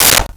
Plastic Bowl 01
Plastic Bowl 01.wav